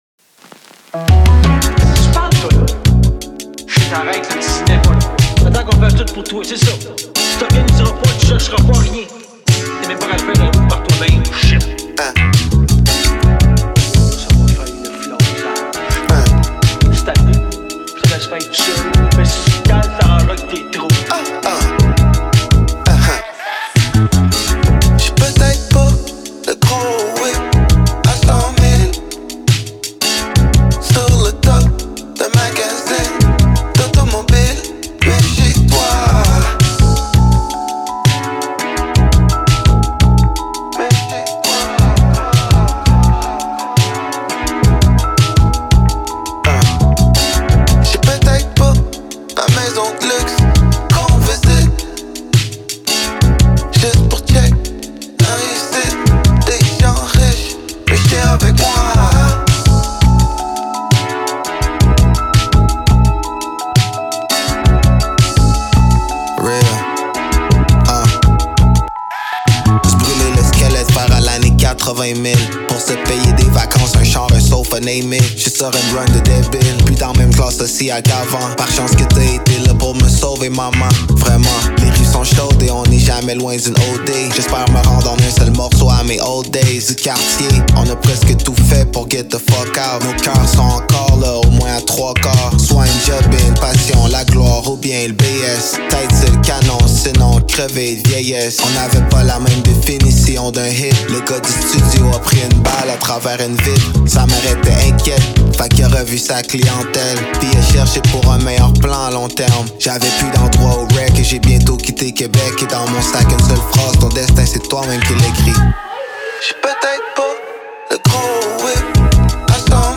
allant de slow jam à new jack swing